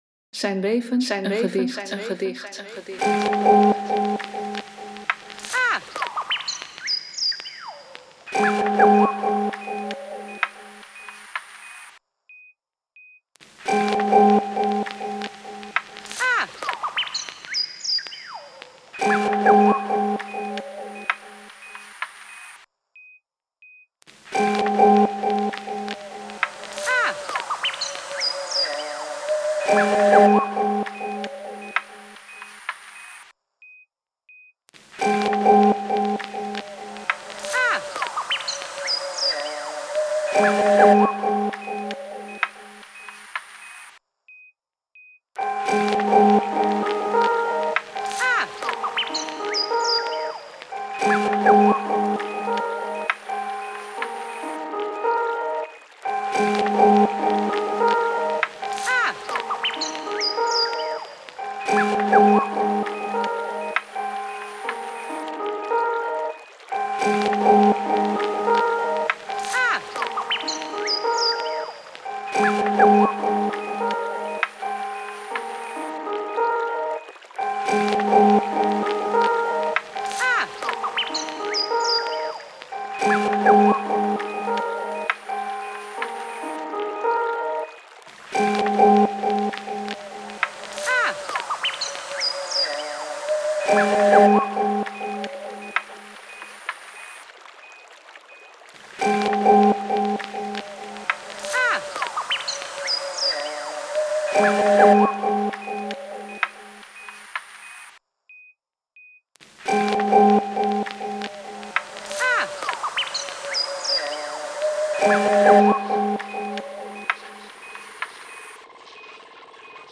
En met geluidjes.
bird-nescio-v1.mp3